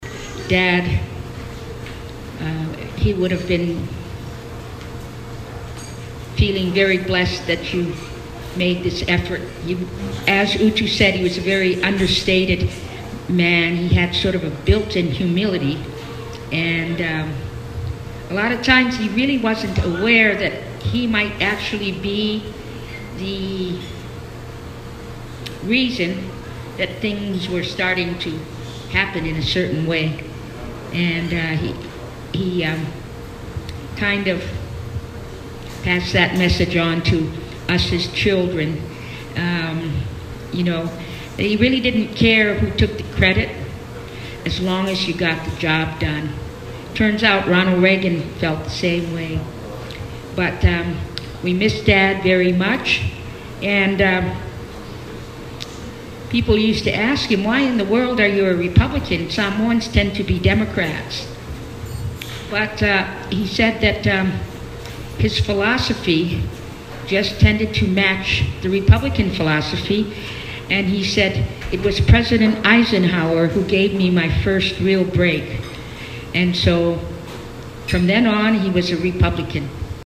One of Uifaatali’s 12 children and American Samoa’s Congressional Delegate Aumua Amata spoke to the audience.
Amata-at-Coleman-Dinner.mp3